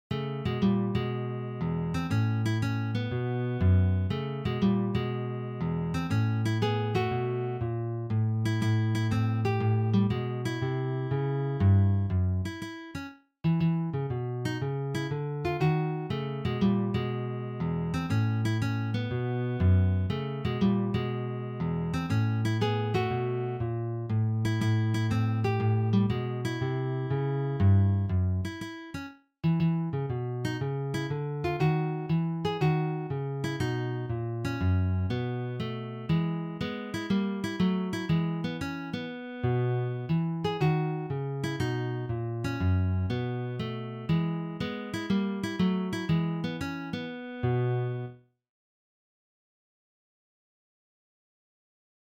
Jazz/Improvisierte Musik
Sololiteratur
Gitarre (1)
Dieses Stück präsentiert sich beschwingt und locker.